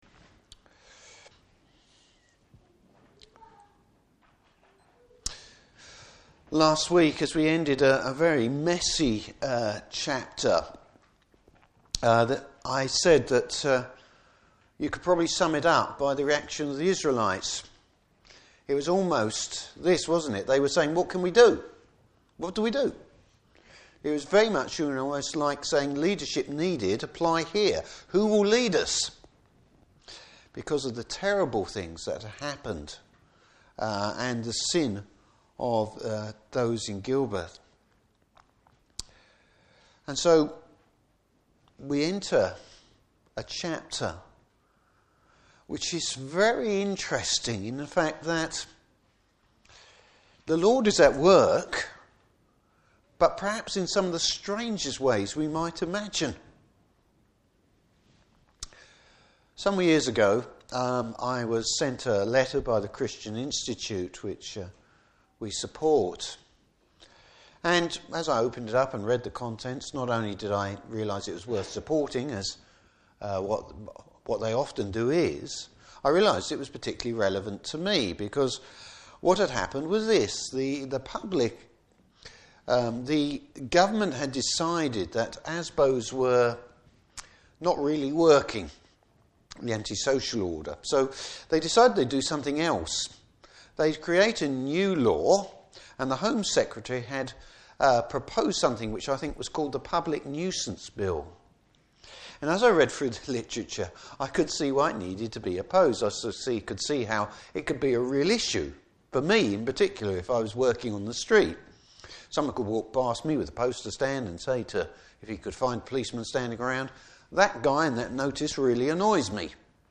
Service Type: Evening Service Bible Text: Judges 20.